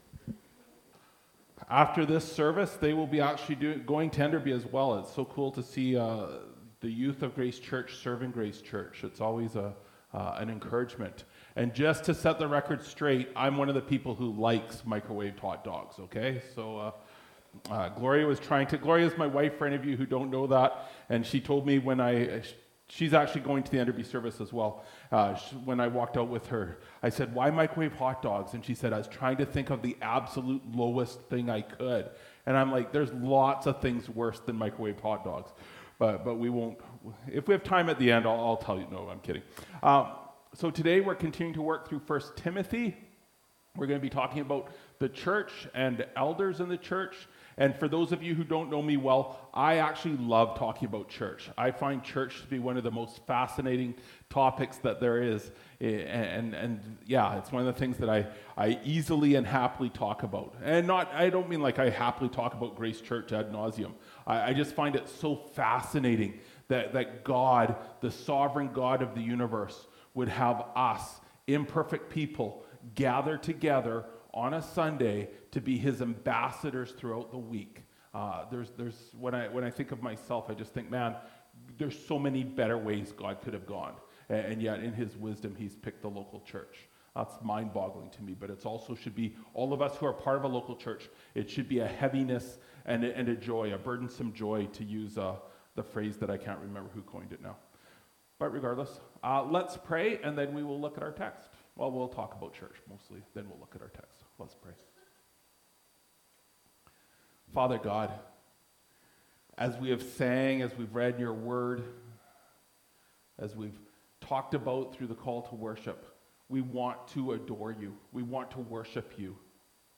May 18, 2025 Leadership in the Local Church – Part 3 (Titus 1:5-9) MP3 SUBSCRIBE on iTunes(Podcast) Notes Discussion This sermon was recorded in Salmon Arm and preached in both campuses.